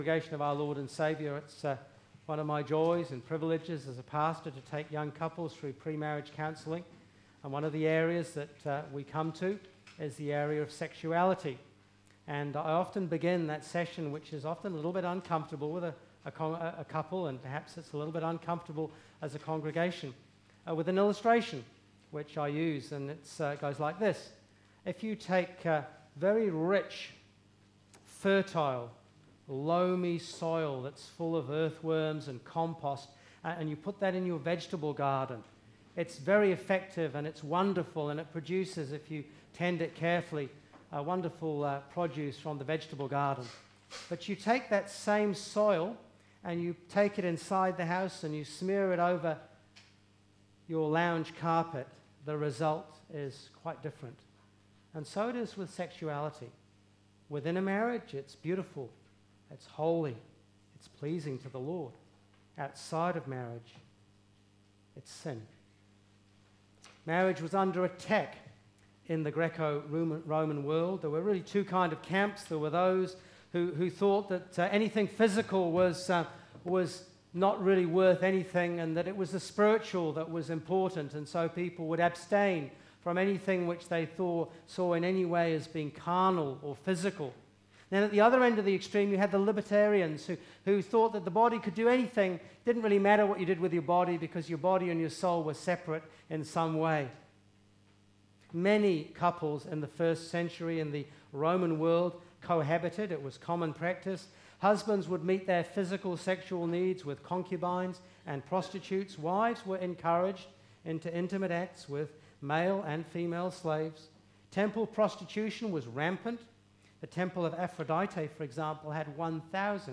Service Type: Afternoon